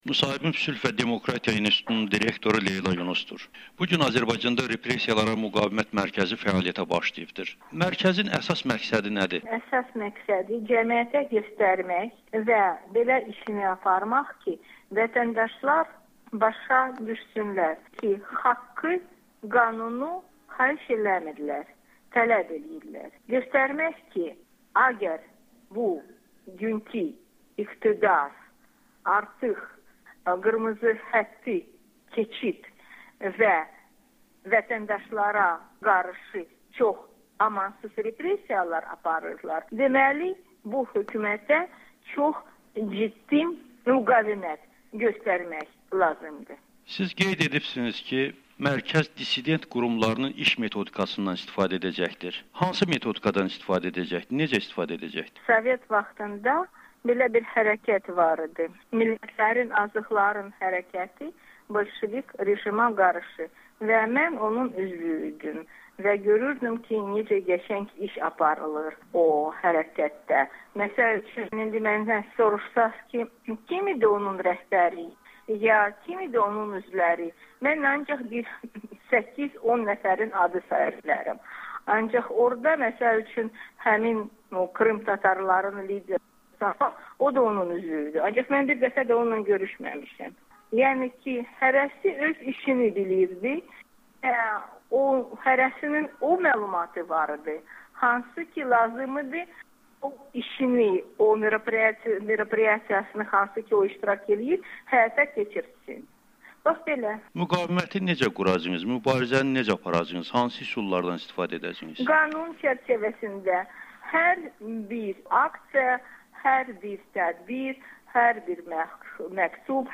Leyla Yunus: Hökumətə çox ciddi müqavimət göstərmək lazımdır [Audio-müsahibə]
Leyla Yunusla müsahibə